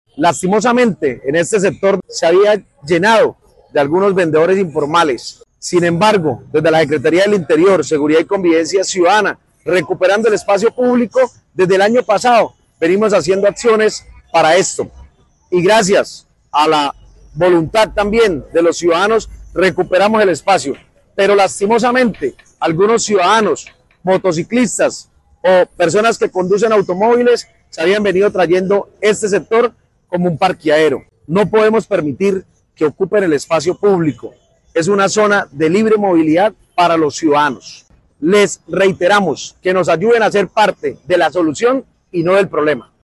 Andrés Ardila, secretario del interior de Floridablanca